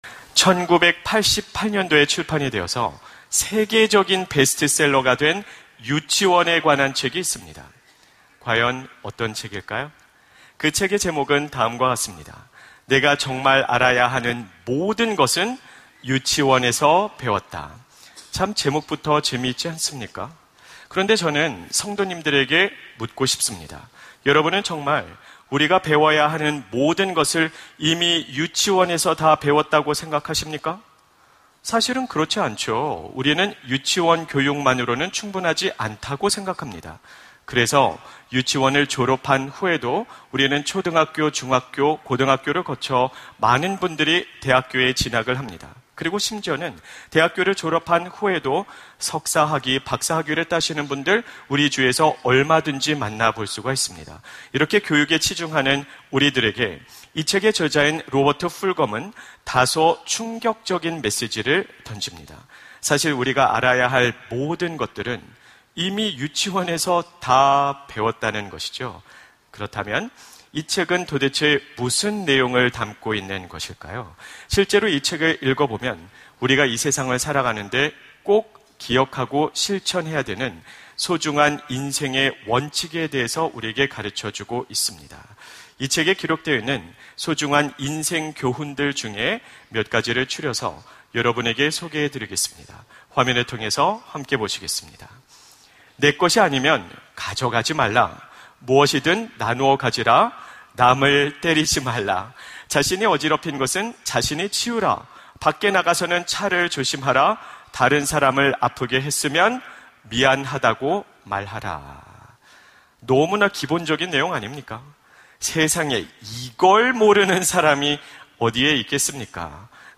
설교 : 주일예배 다시 기본으로!